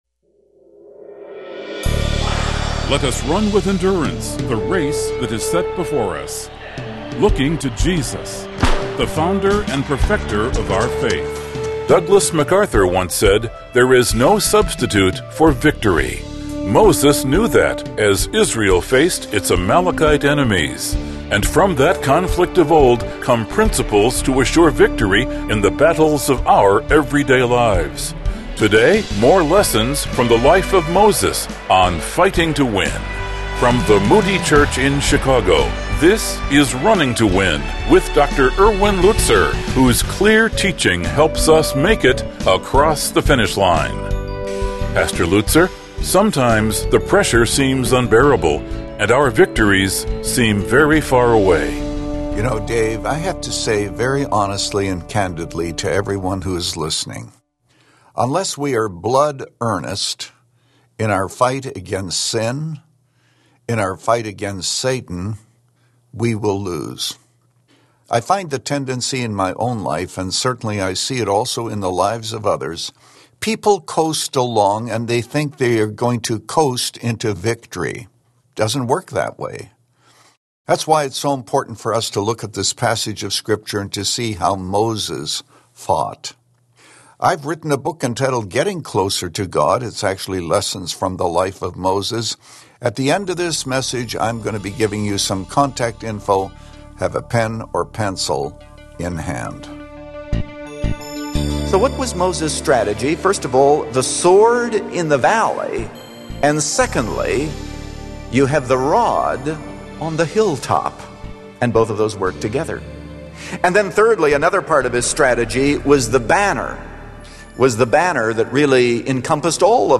In Exodus 17, Moses prayed on the hilltop while Joshua fought in the valley. In this message, Pastor Lutzer shows us how our hilltop prayers can influence all kinds of outcomes down in the valley.